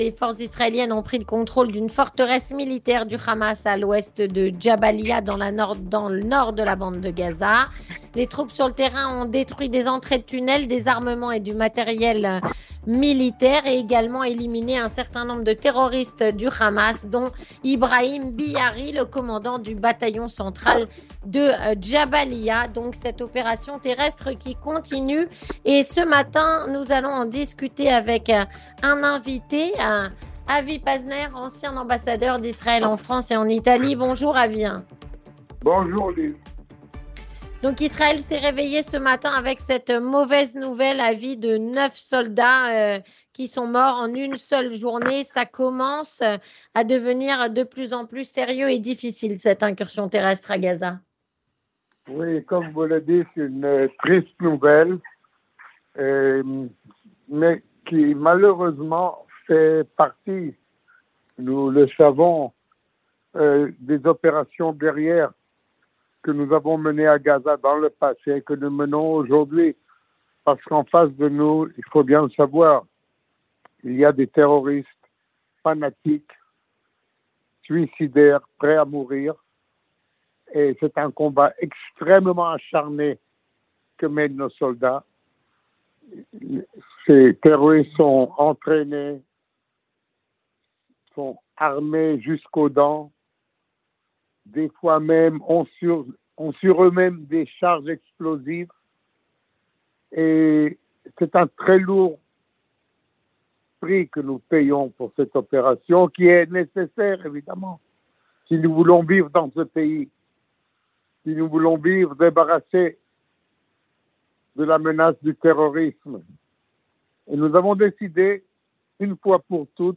Avec Avi Pazner, ancien  ambassadeur d'Israël en France et en Italie